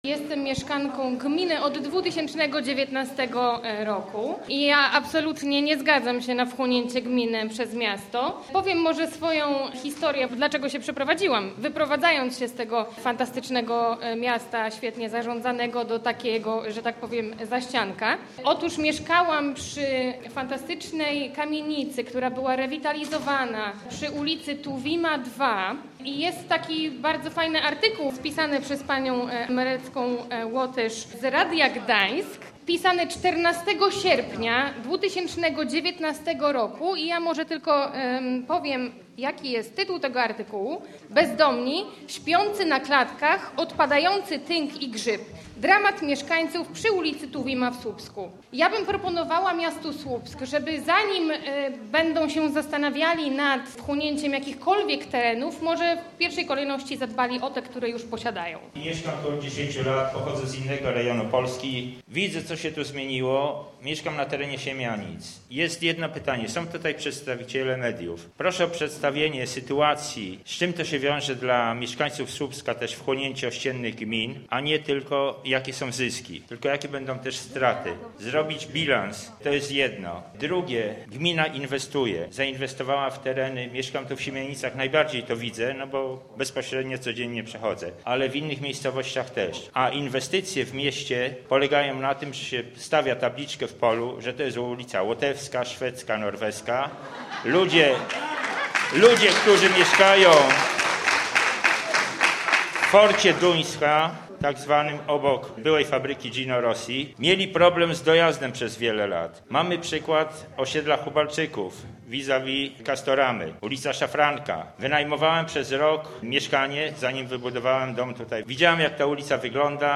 Pełna sala mieszkańców Siemianic, Swochowa i Niewierowa przybyła na konsultacje w sprawie poszerzenia granic Słupska.
Zapraszamy do wysłuchania relacji ze spotkania w Siemianicach